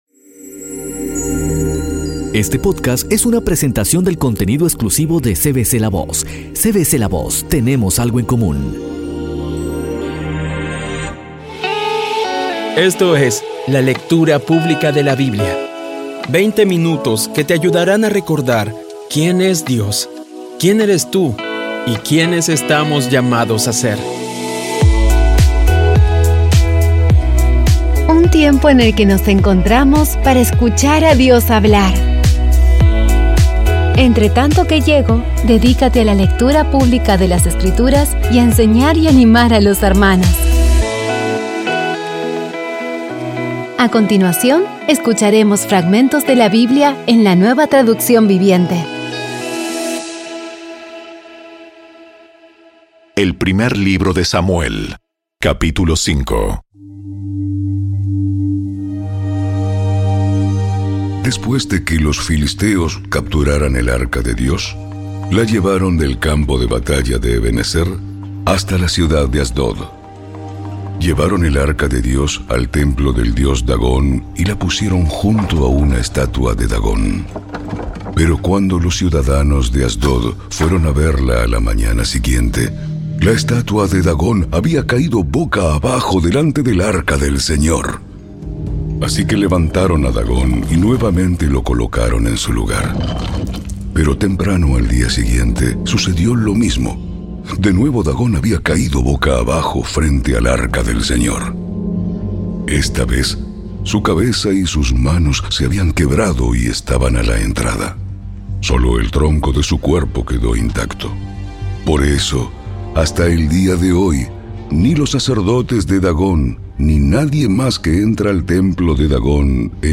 Audio Biblia Dramatizada Episodio 3